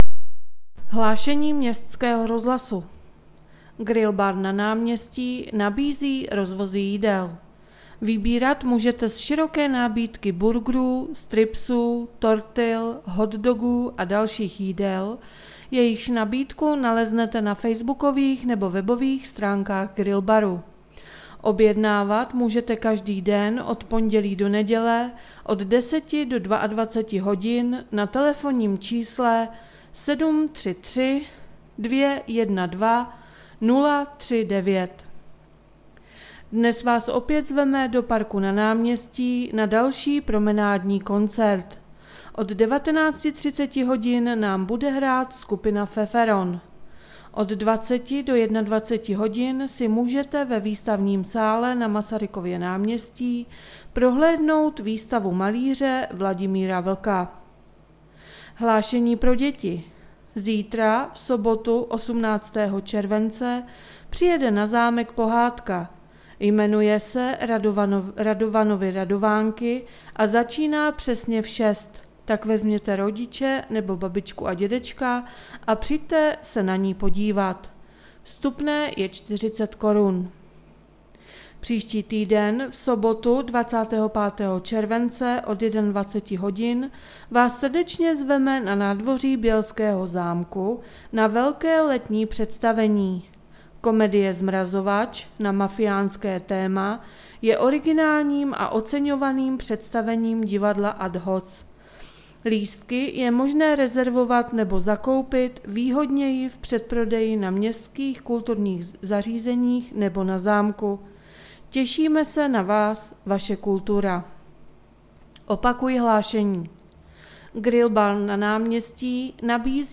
Hlášení městského rozhlasu 17.7.2020